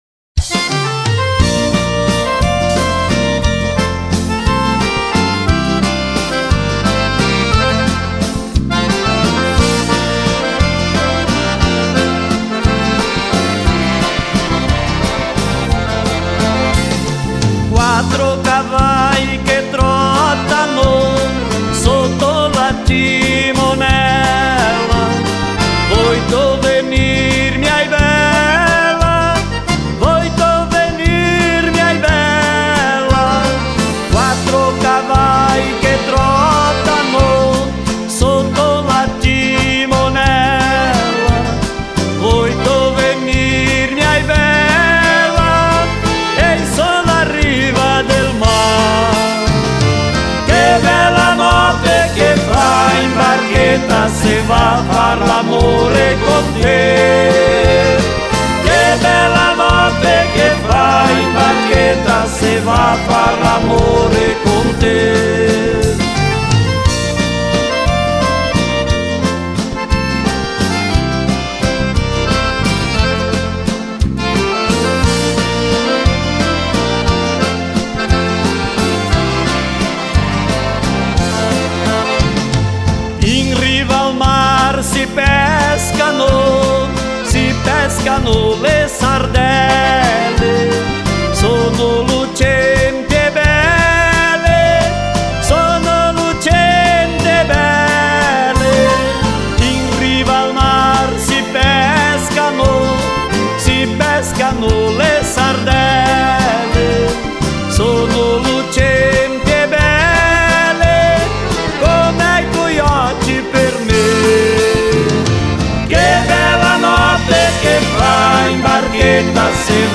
EstiloSertanejo
Composição: Folclore Italiano.